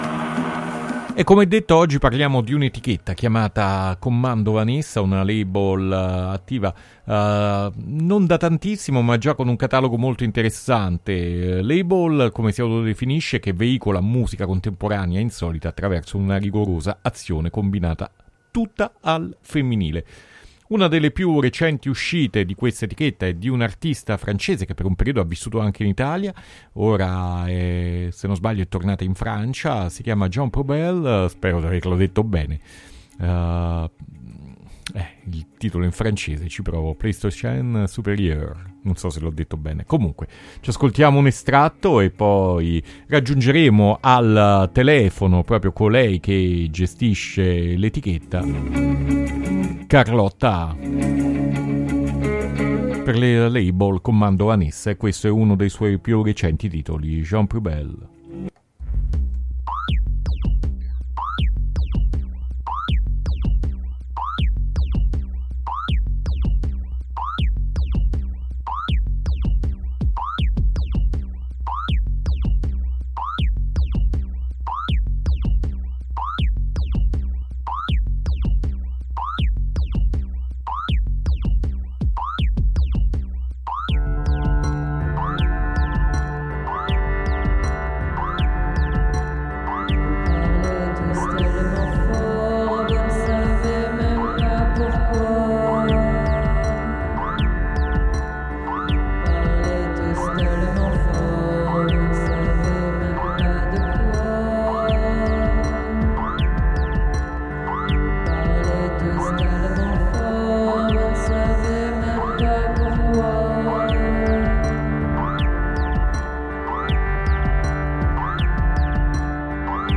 Intervista alla label Commando Vanessa